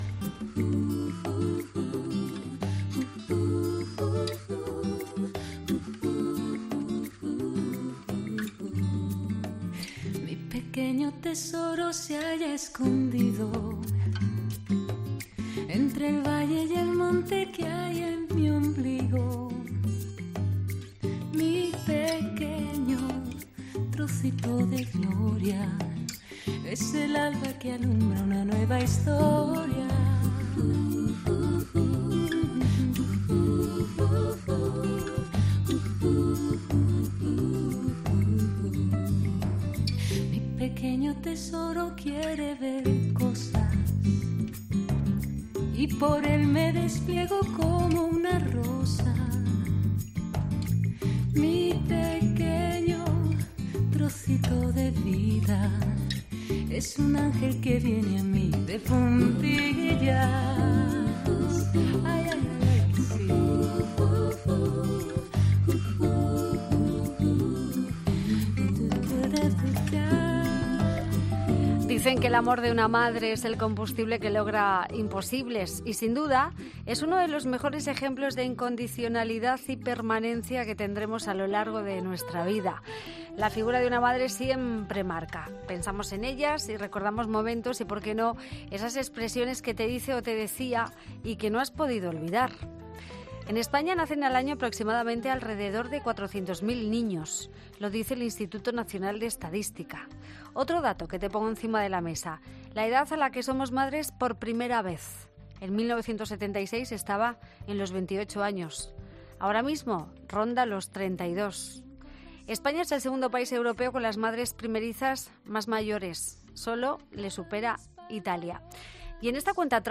Escucha la entrevista completa para celebrar el Día de la Madre